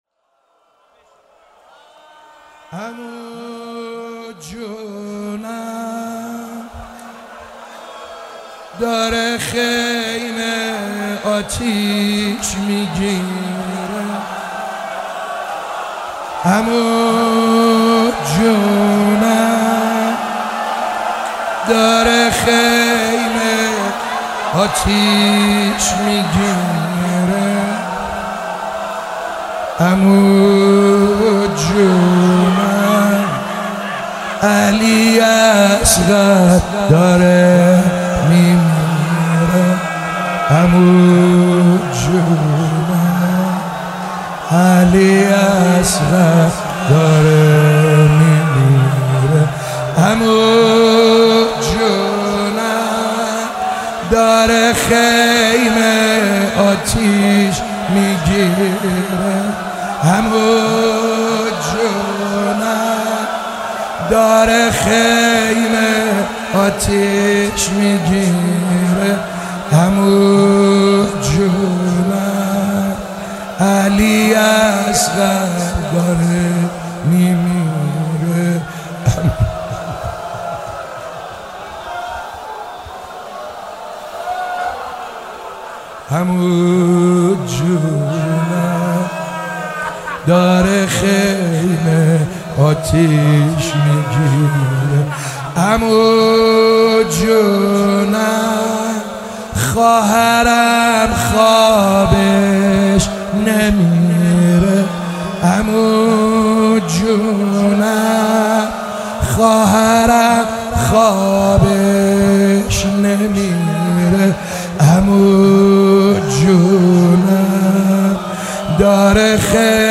شب تاسوعا محرم96 - شعرخوانی - عمو جونم داره خیمه آتیش میگیره
محرم96 سعید حدادیان شعرخوانی مداحی